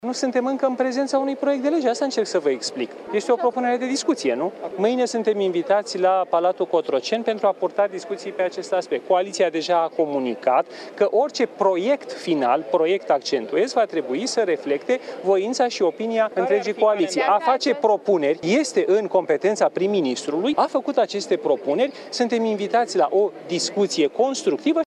Ministrul Justiției, Radu Marinescu: „A face propuneri este în competența prim-ministrului”